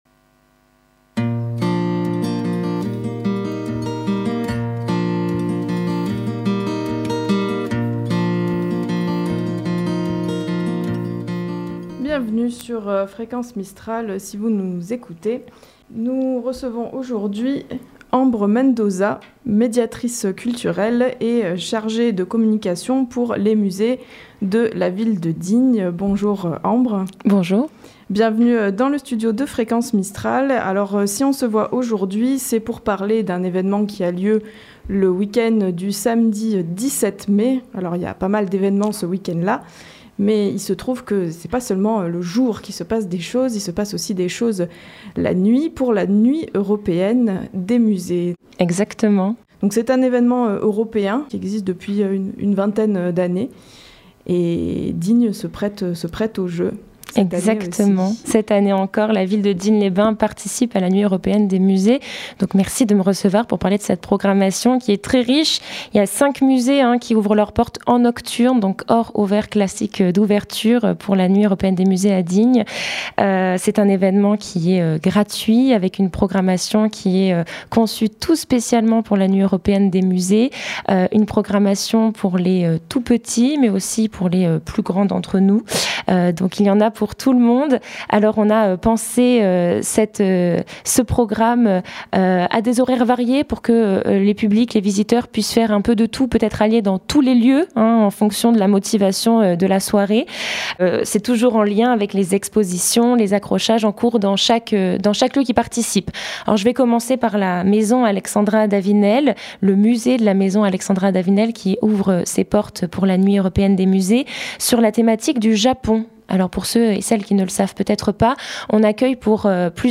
était notre invitée pour détailler le programme de la nuit européenne des musées